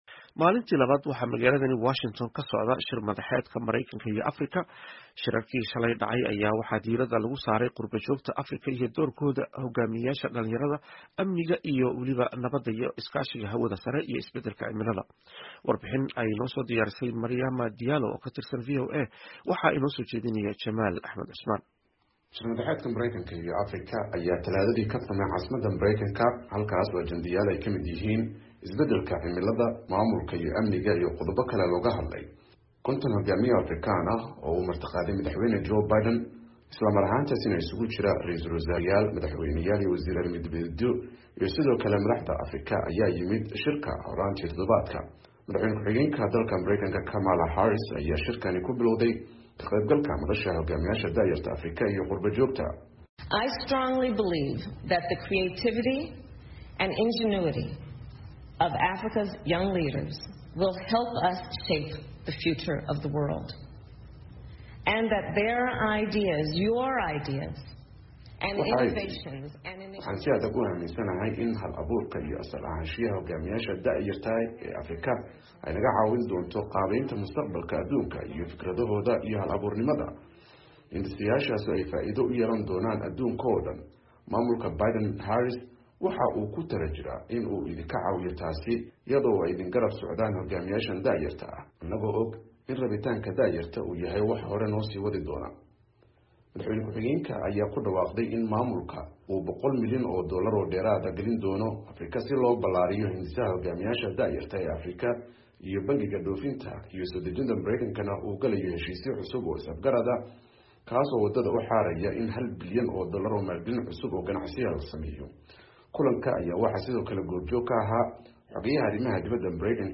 Warbixin ku saabsan maalintii labaad ee shir madaxeedka Mareykanka iyo Afrika